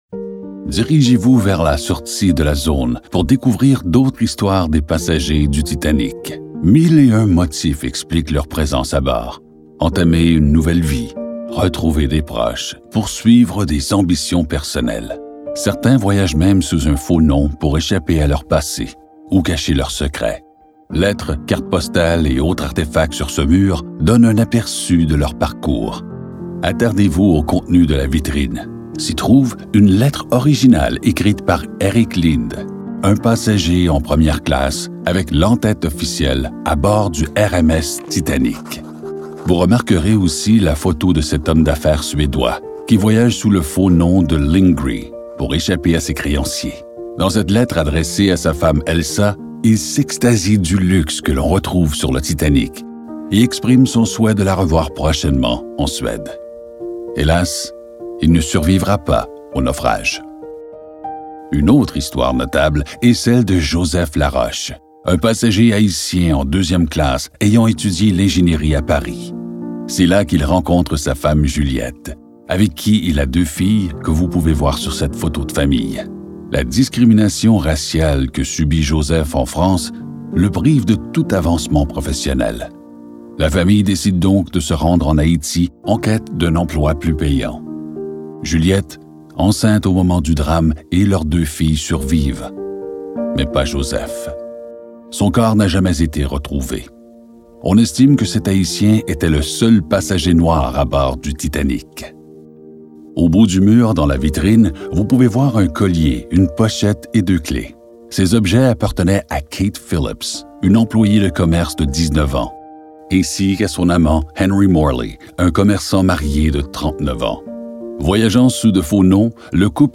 • Le déploiement muséologique extrêmement fort et saisissant génère une vive émotion, décuplée grâce à un audioguide diffusant une trame narrative des plus bouleversantes, redonnant vie à ceux et celles qui ont vécu la funeste nuit du 14 au 15 avril 1912.
titanic-audioguide-histoires-passagers.mp3